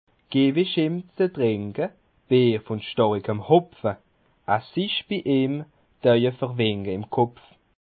Bas Rhin
Ville Prononciation 67
Schiltigheim